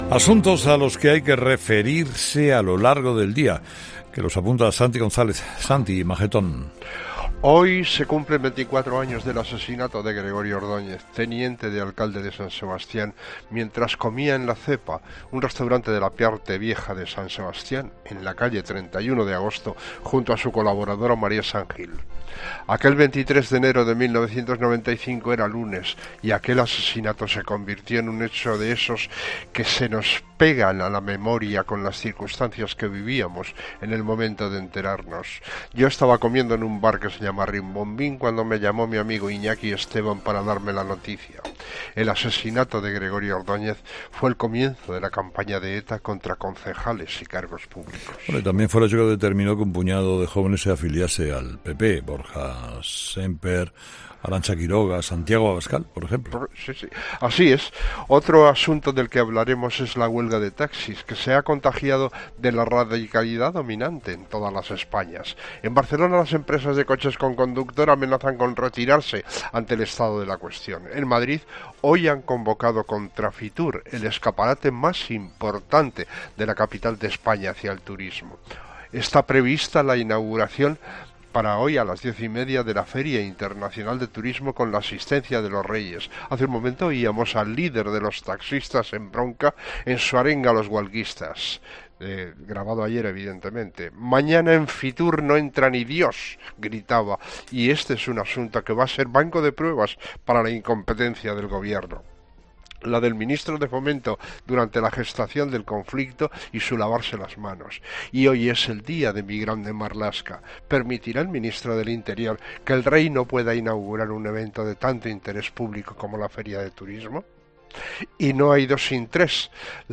El análisis de la actualidad de Santi González en 'Hererra en COPE'.